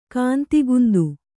♪ kāntigundu